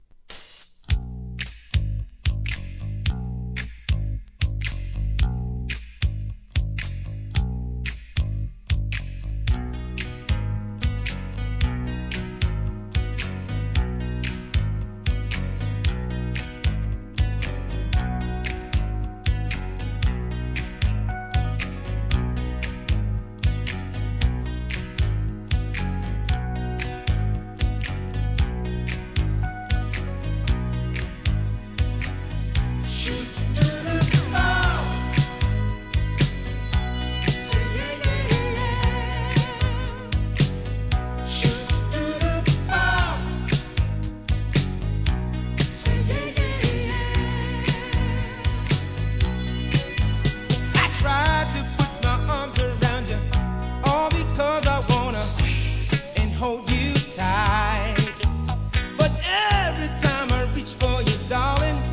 ¥ 770 税込 関連カテゴリ SOUL/FUNK/etc...